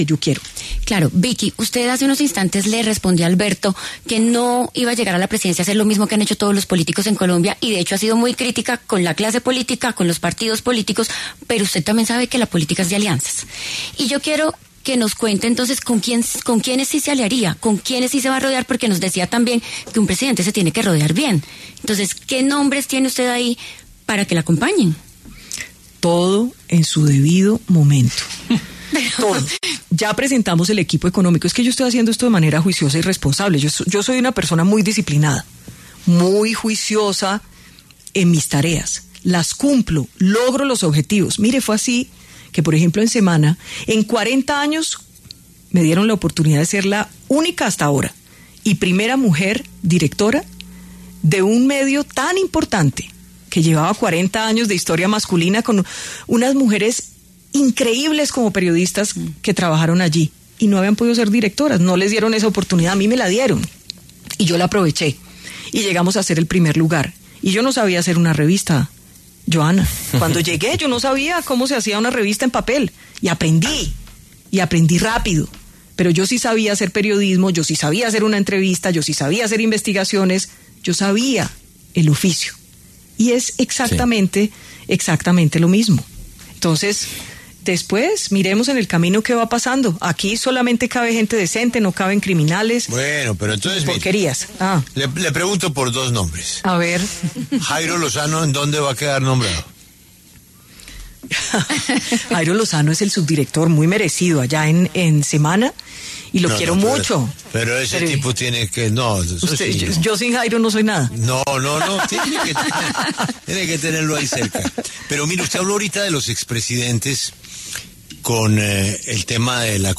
Vicky Dávila, precandidata presidencial, en diálogo con La W, se refirió a las posibles alianzas y ayudas que recibiría en su mandato en caso de ser elegida, por el pueblo, como presidenta de Colombia.